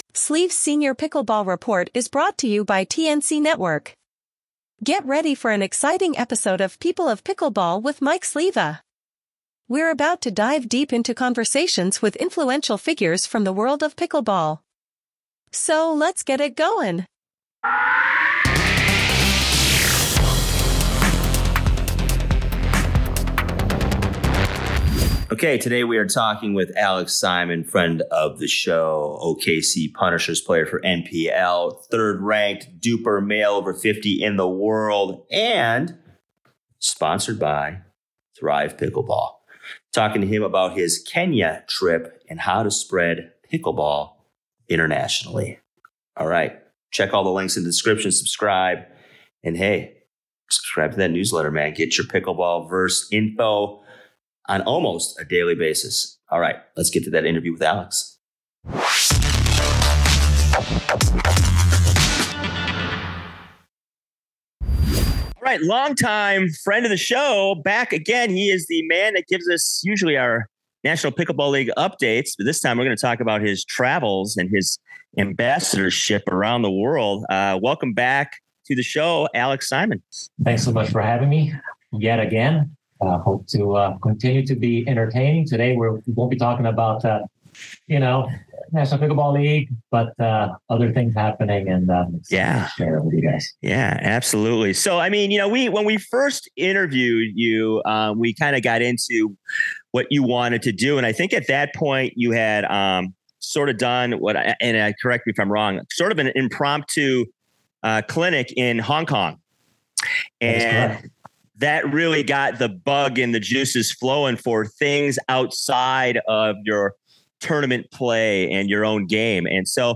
Tune in now for an unforgettable conversation that showcases the best of pickleball and the power of making a difference.